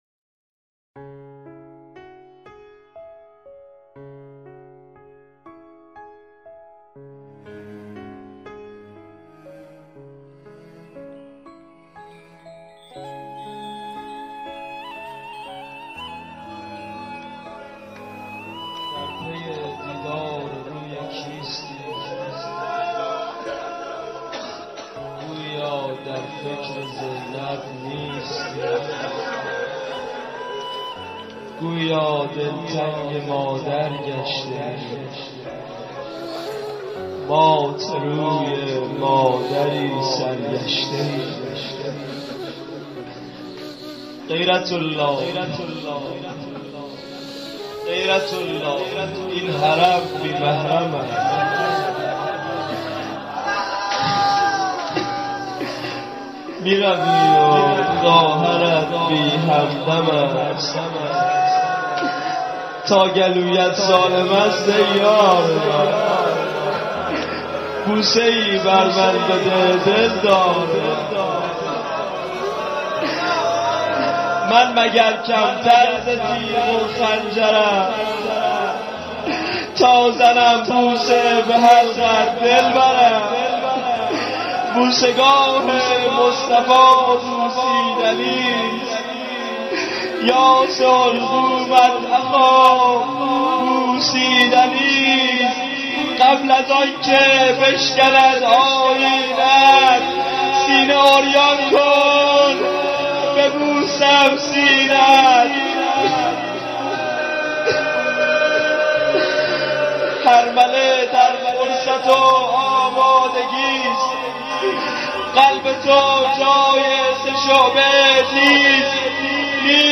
اجراشده در هیات رهروان شهدا بندرامام خمینی